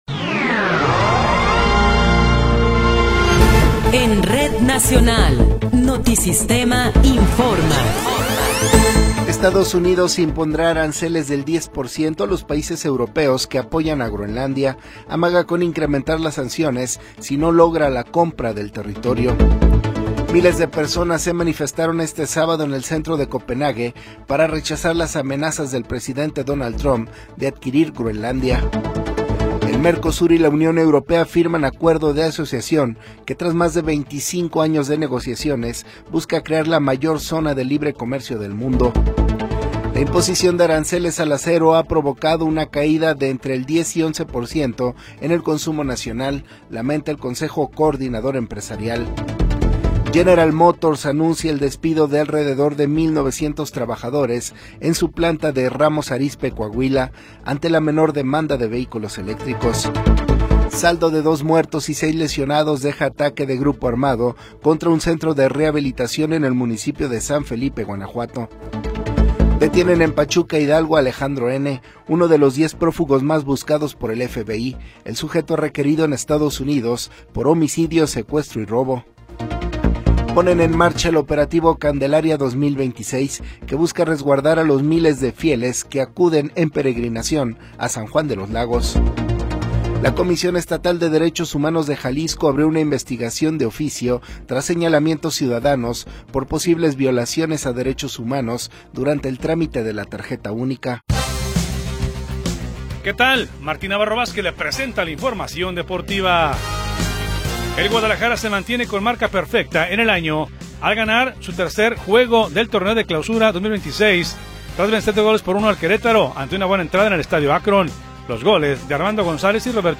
Noticiero 21 hrs. – 17 de Enero de 2026